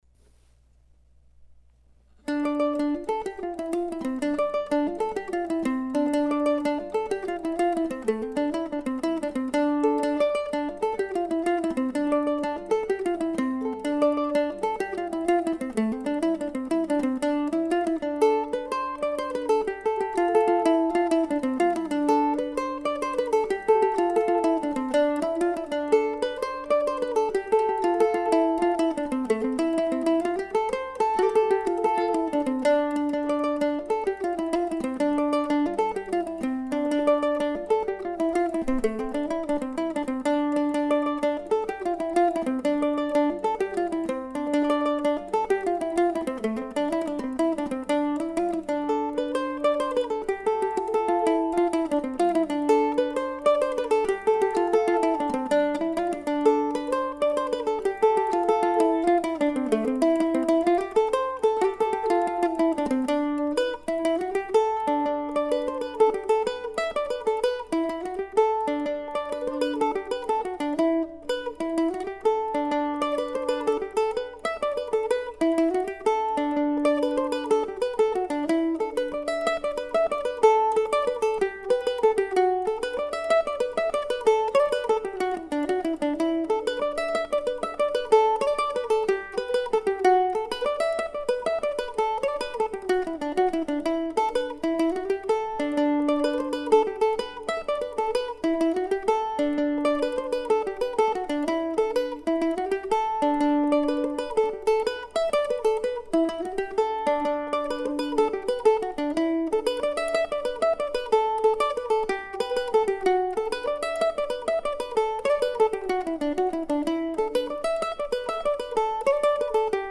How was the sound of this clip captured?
It's fun to play solo also.